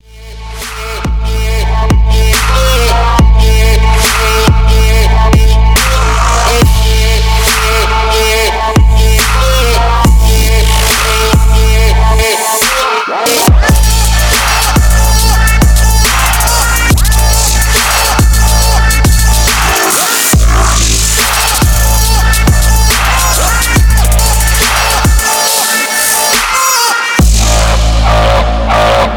Elektronisk musik